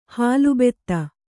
♪ hālu betta